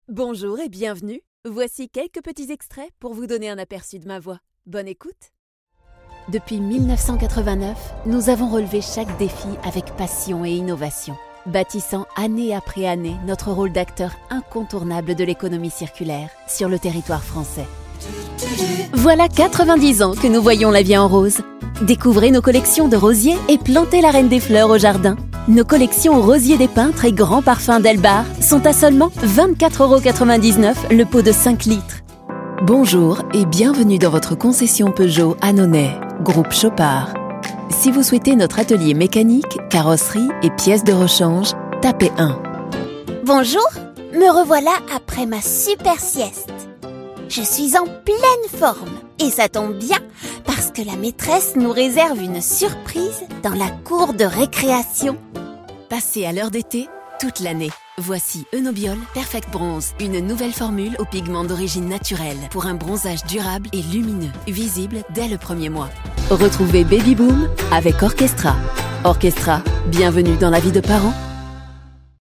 "Vous êtes à la recherche d'une voix dynamique, enjouée, girly, ou encore douce, sensuelle, posée?
Je dispose d'un home-studio professionnel équipé:
d'une cabine STUDIOBRICKS ONE
d'un micro NEUMANN TLM 102